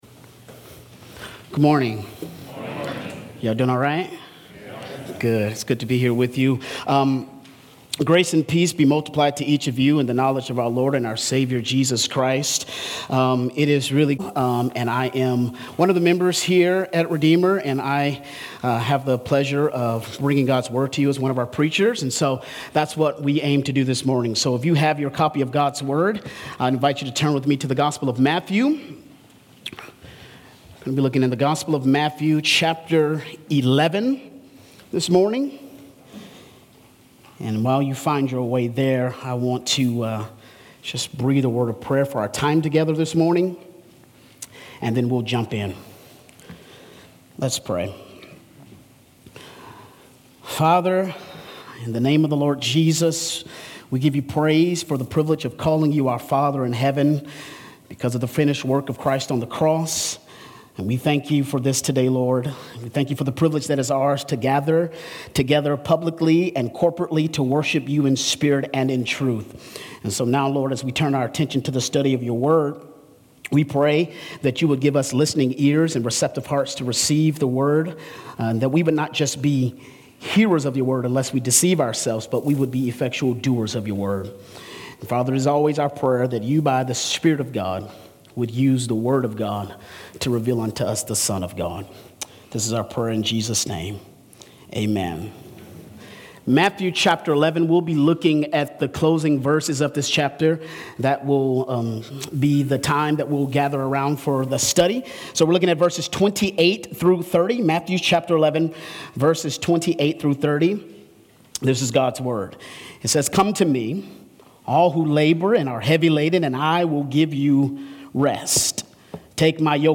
Redeemer Fellowship Sermons Podcast - Come and Rest | Free Listening on Podbean App